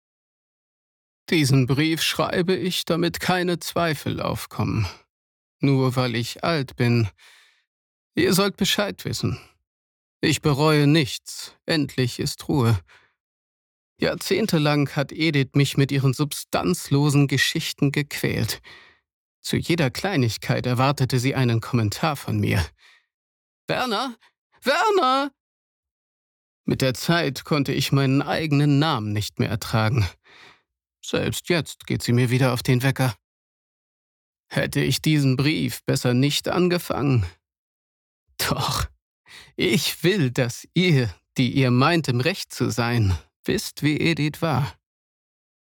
Ich habe mir erlaubt, deinem Aufruf zu folgen und einen kleinen Teil von Werner eingesprochen.